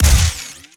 GUNTech_Sci Fi Shotgun Fire_06_SFRMS_SCIWPNS.wav